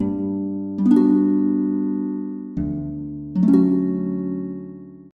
Beautiful, Simple Ringtone.